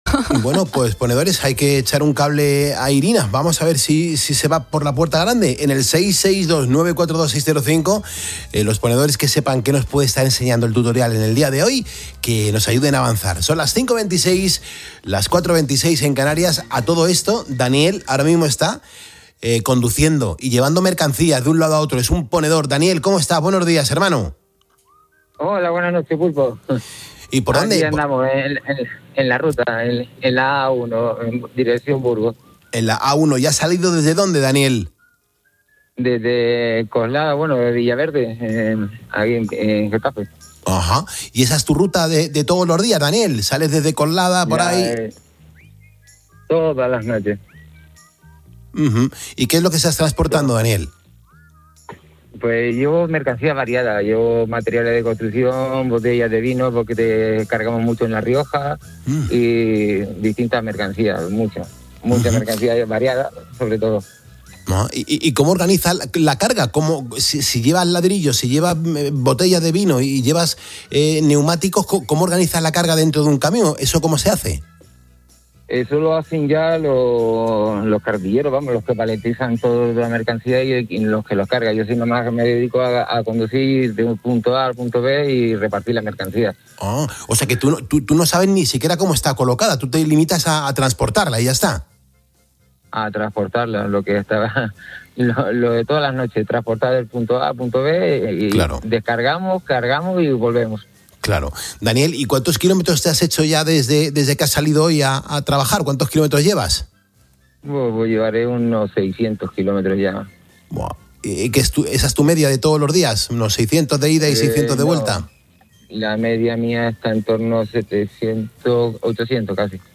"Hago todas las noches en torno a 700 o 800 kilómetros", ha confesado en directo en COPE. Su ruta nocturna parte de Villaverde, en Madrid, y tiene como destino principal Burgos, un trayecto que forma parte de su rutina diaria como transportista.